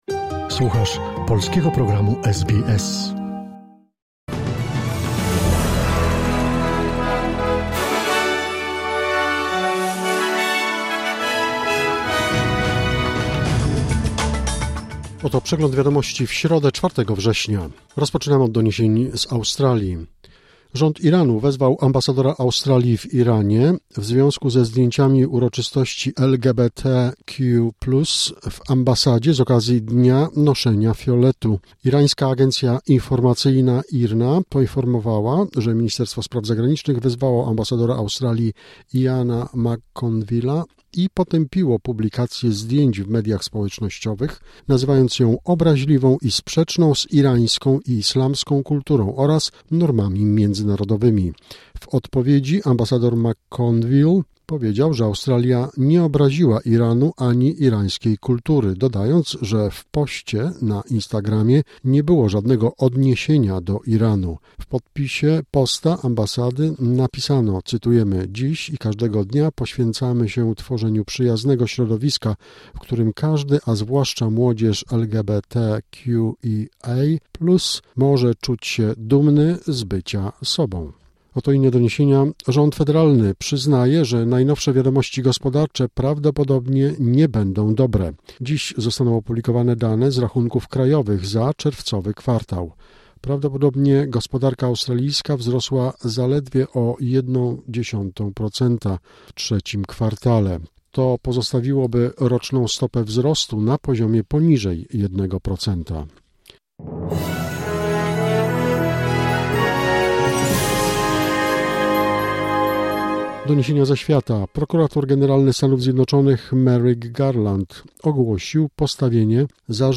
Wiadomości 4 września 2024 SBS News Flash